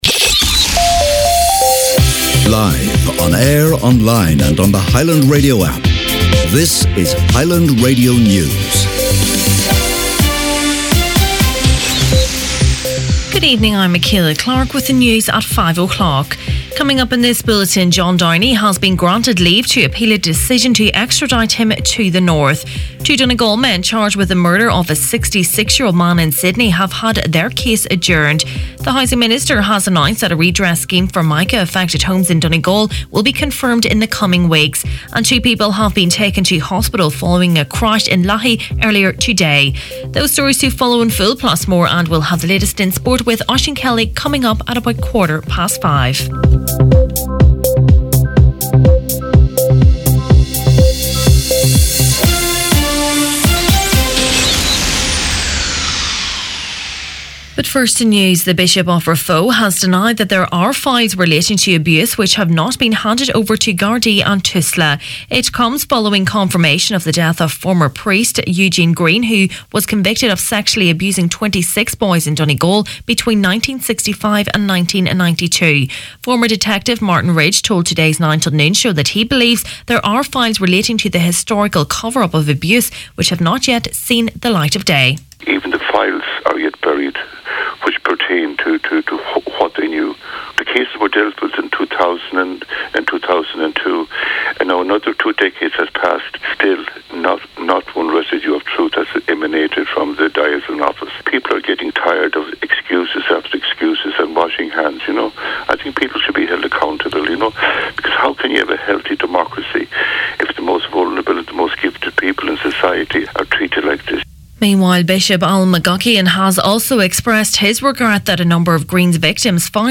Main Evening News, Sport and Obituaries Wednesday March 6th